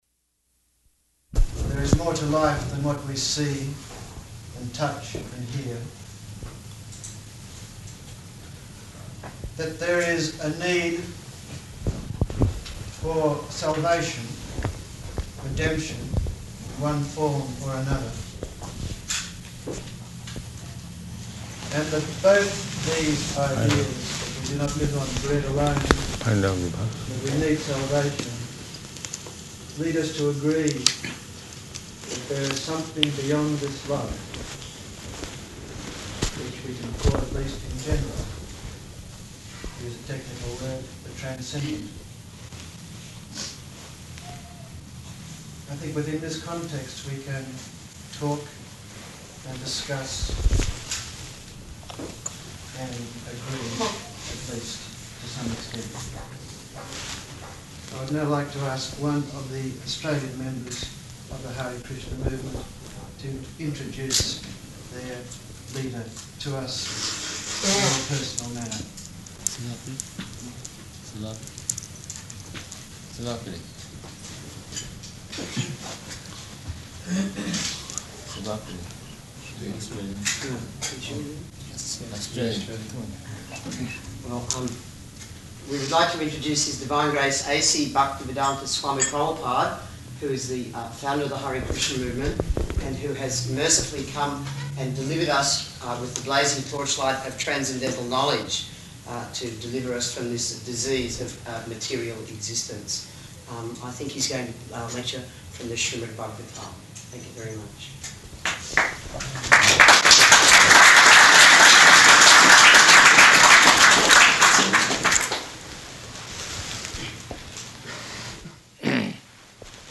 Lecture at St. Pascal's Franciscan Seminary
Type: Lectures and Addresses